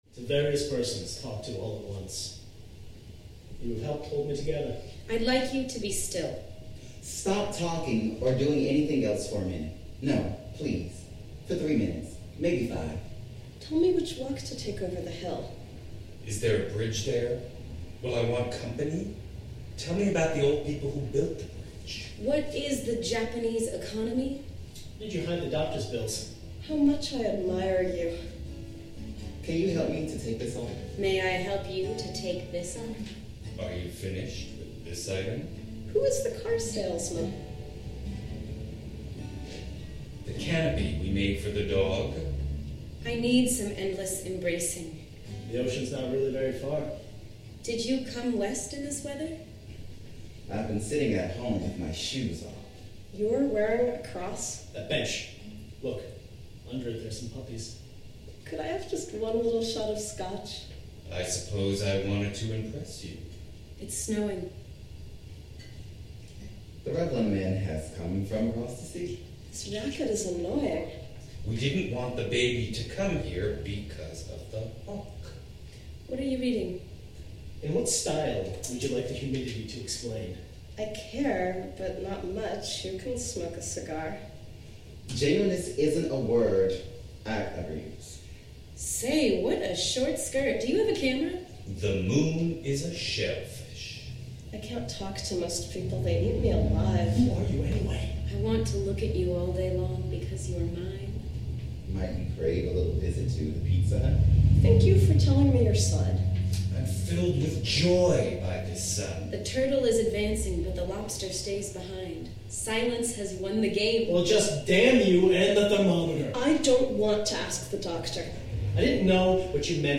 Koch C: A capella: just the text of the poem.
Recorded on Saturday, September 4 at the Blue Room at the Baltimore Free School.